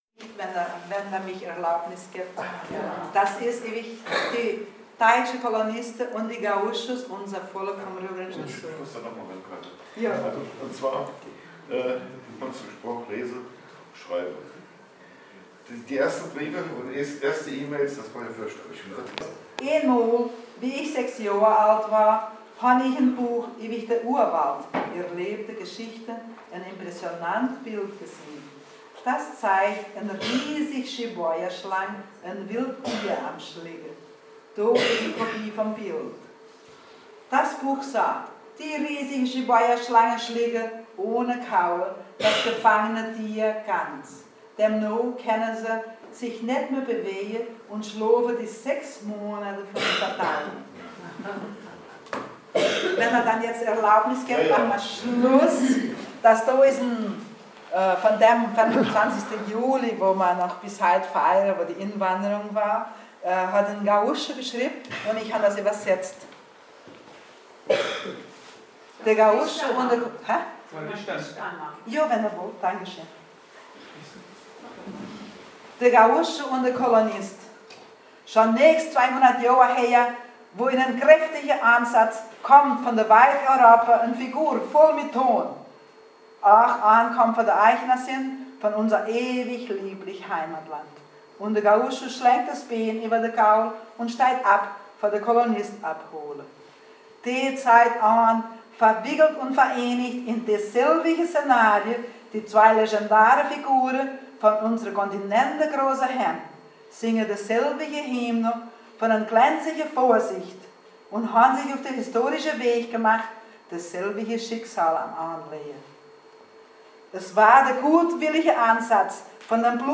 in Buch einen Vortrag hielt.